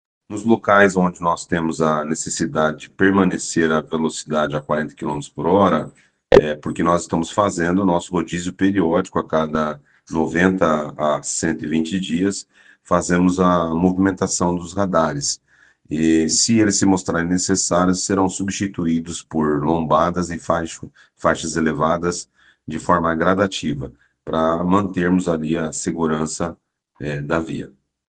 Os radares de 40km/h serão substituídos por lombadas ou redutores de velocidade. Ouça o que diz o secretário: